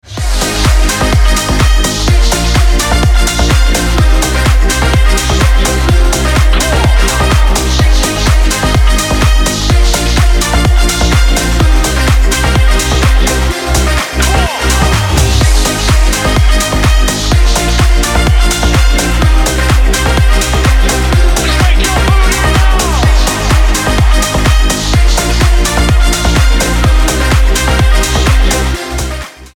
• Качество: 320, Stereo
позитивные
громкие
веселые
женский голос
энергичные
ремиксы
slap house